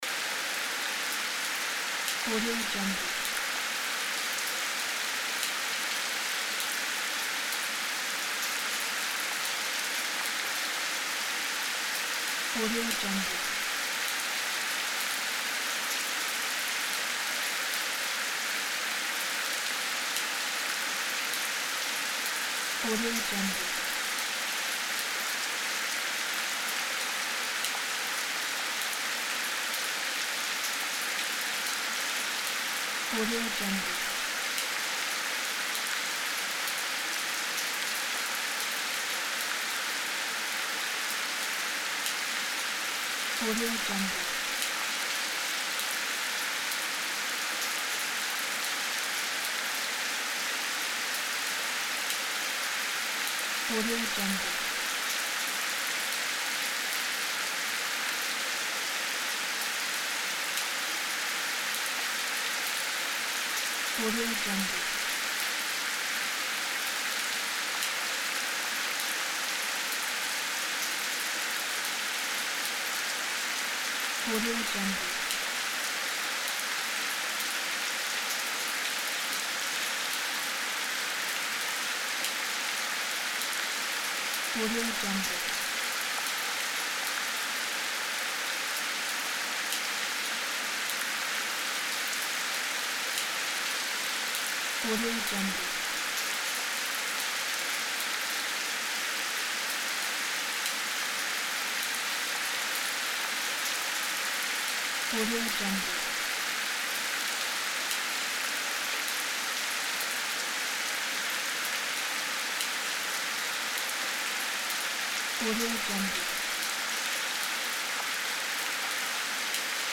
دانلود افکت صوتی صدای بارش آرام باران